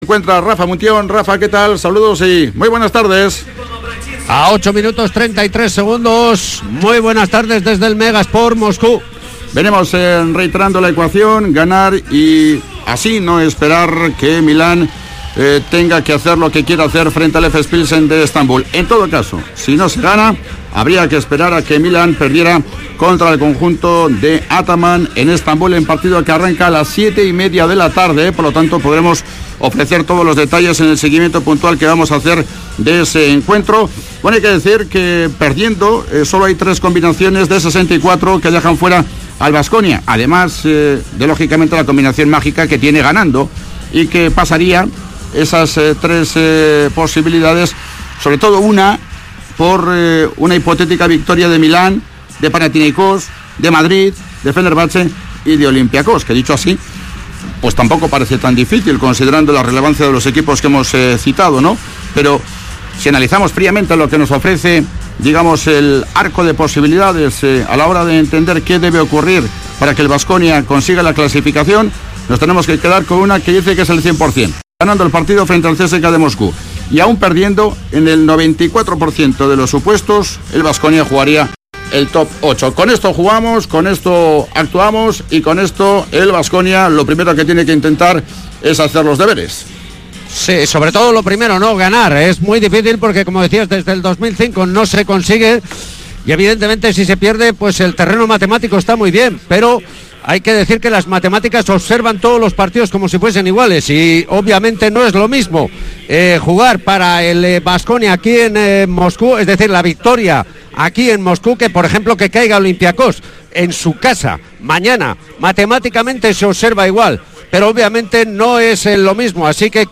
CSKA-Kirolbet Baskonia jornada 30 euroleague 2018-19 retransmisión Radio Vitoria